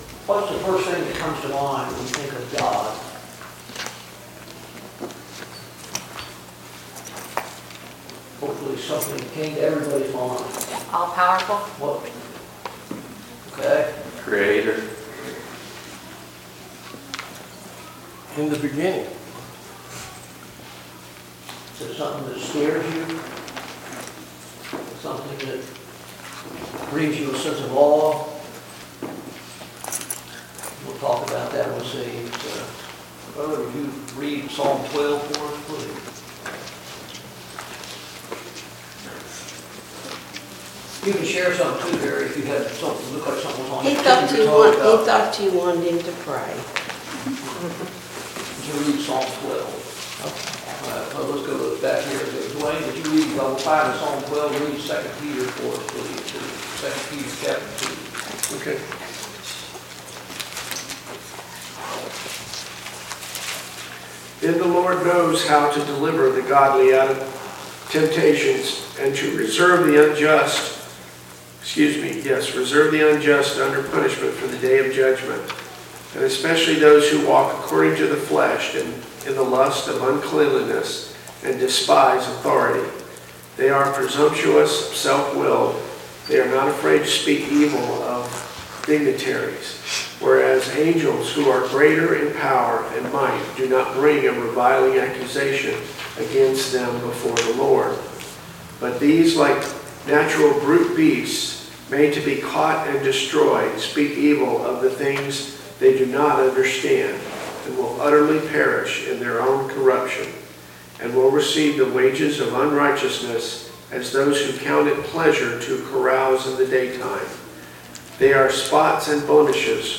Study on the Minor Prophets Passage: Hosea 2:1-23 Service Type: Sunday Morning Bible Class « 3.